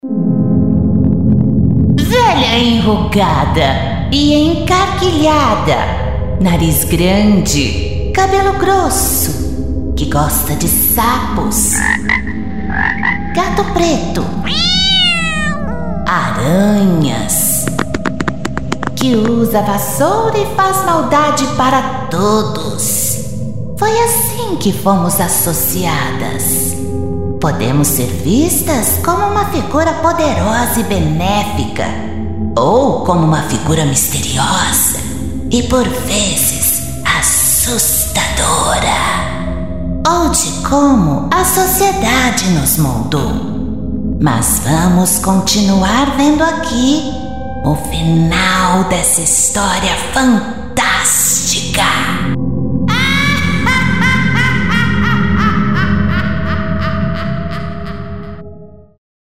voz de bruxa: